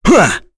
Kain-Vox_Attack3.wav